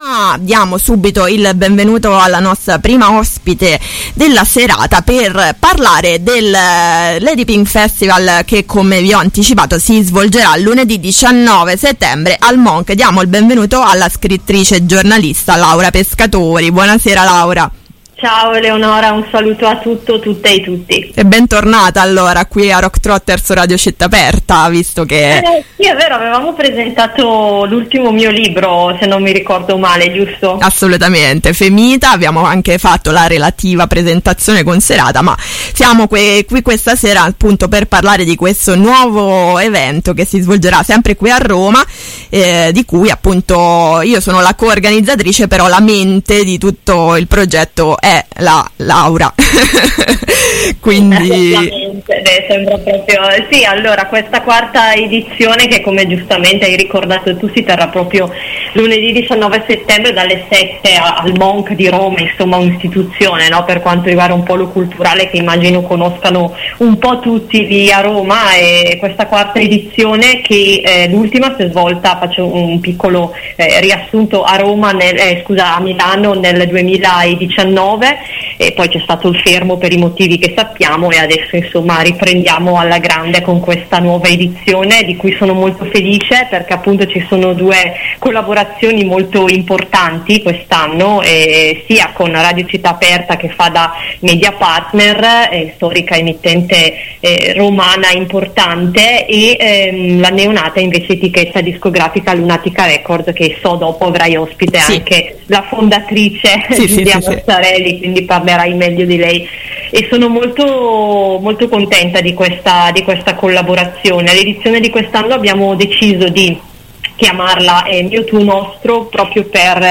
interviste-lady-pink-festival-12-9-22.mp3